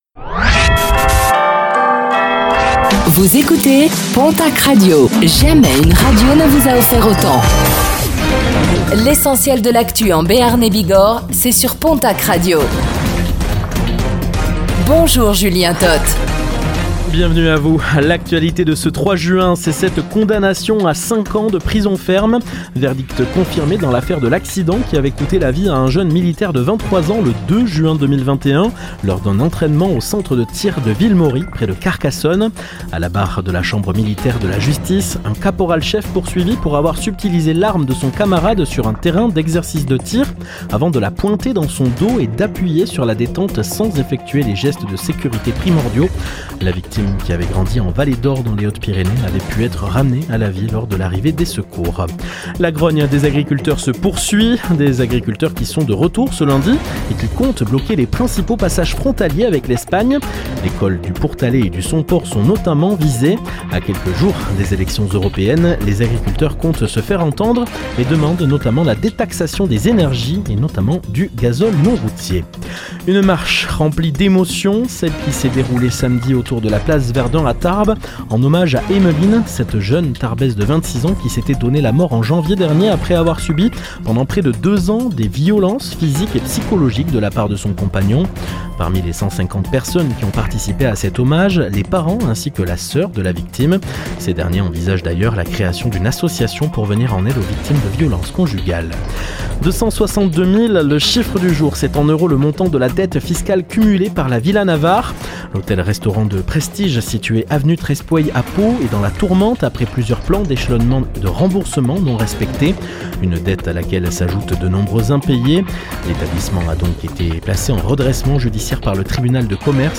Infos | Lundi 03 juin 2024